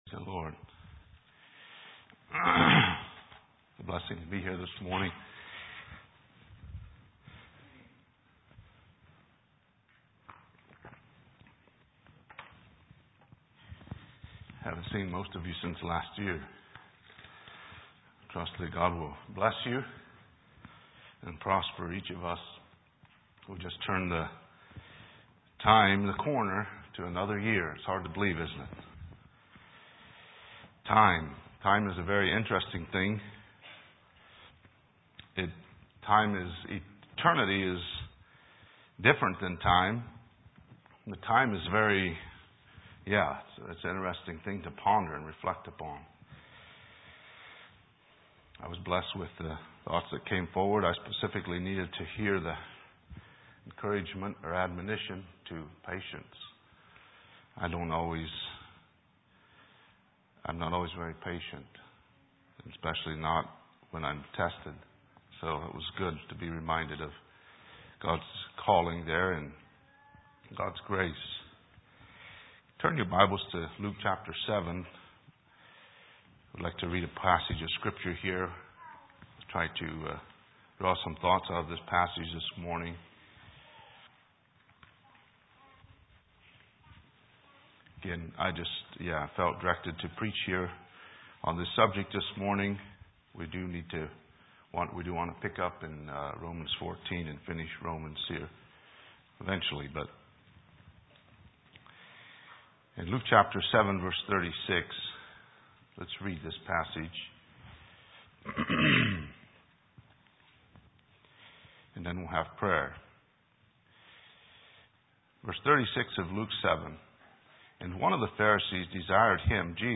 2022 Sermons 12/12